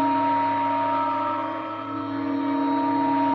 Re-added build noise
build.ogg